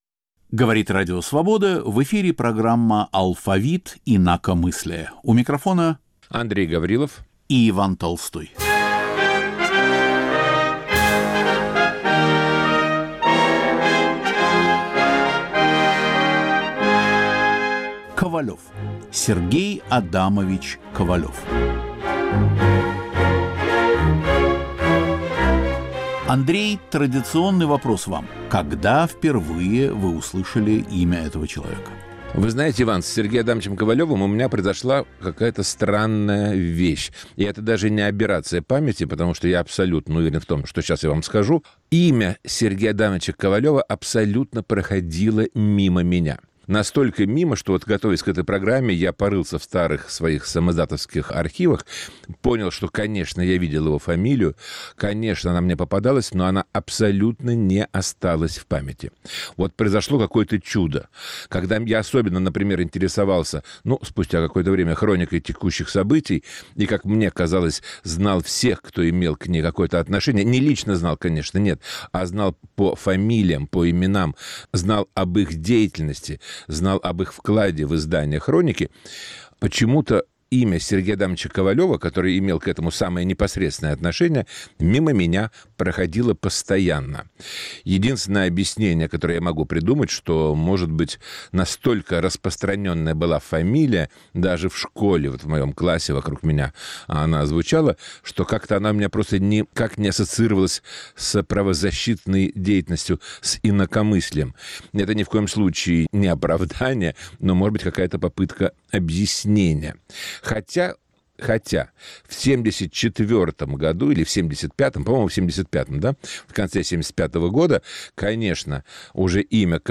В программе старые записи из архива "Свободы" 1970-1990-х годов, голоса дикторов, выступление Сергея Адамовича на одной из перестроечных конференций. Разговор сопровождается любимой музыкой Ковалева.